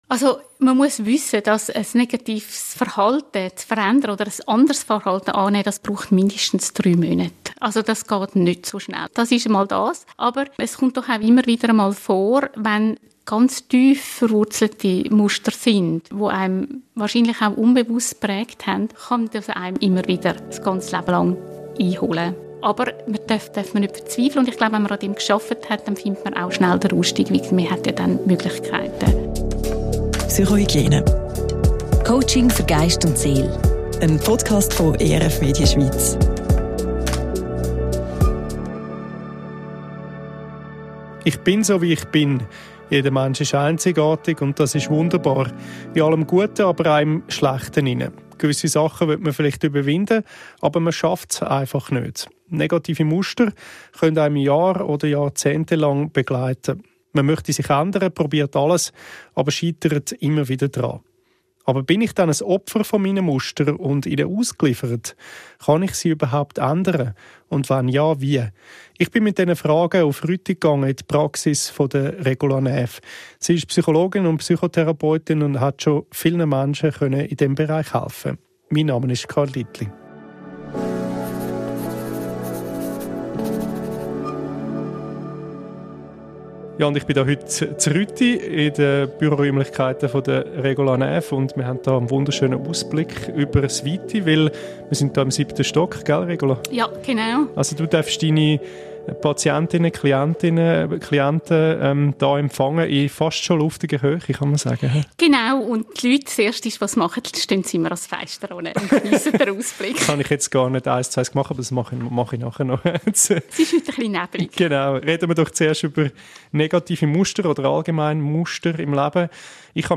in luftiger Höhe im 7. Stock.